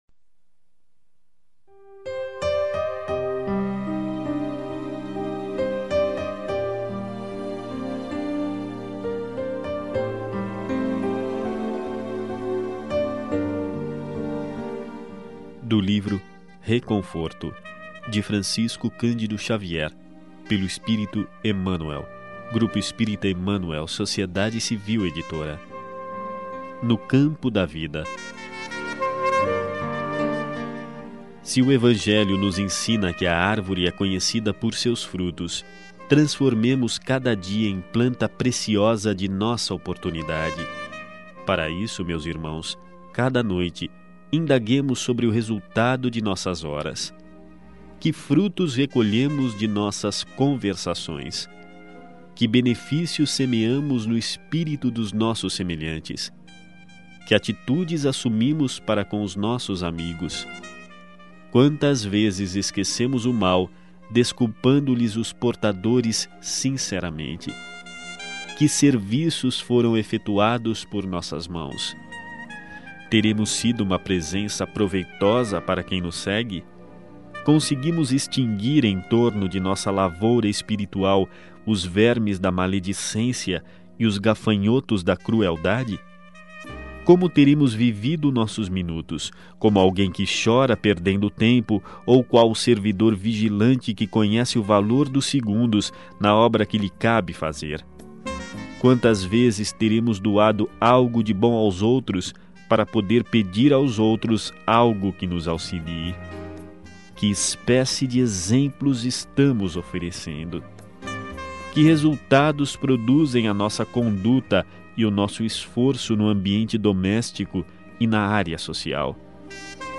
Mensagens em Áudio